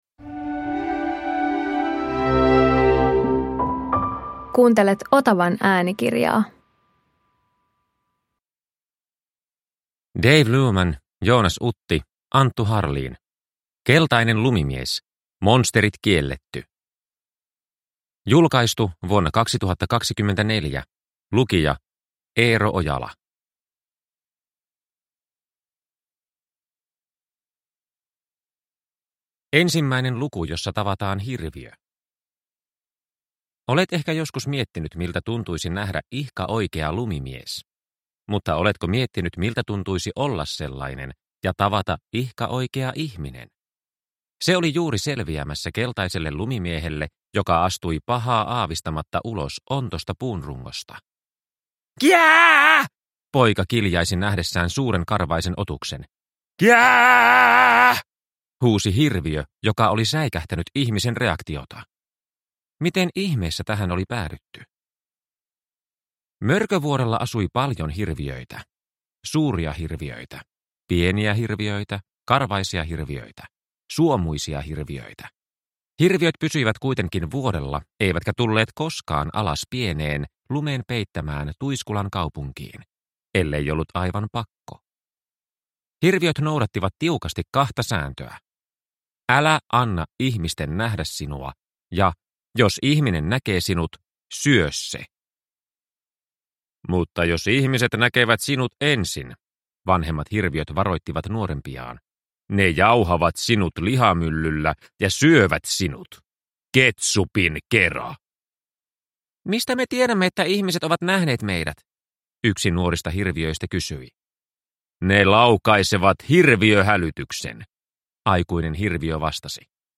Keltainen lumimies - Monsterit kielletty! (ljudbok) av Anttu Harlin